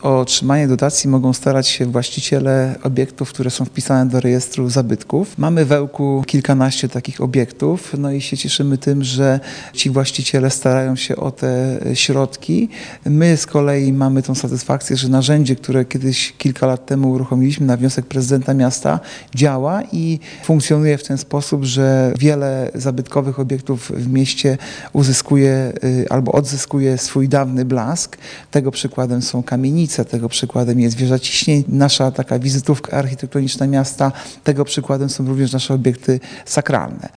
Maksymalna wysokość dofinansowania z budżetu miasta wynosi do 50% nakładów koniecznych na wykonanie prac, mówi Artur Urbański zastępca prezydenta Ełku